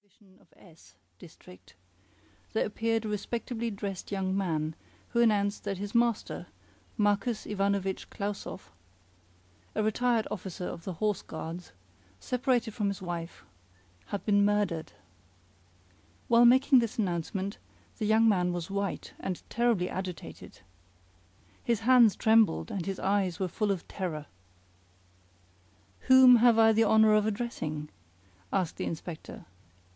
Audio Book
Lebrivox audio book Recording